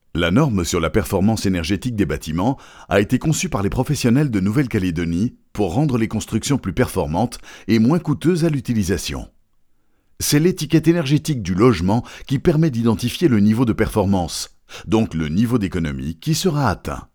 Profundo, Natural, Maduro, Cálida, Suave
Corporativo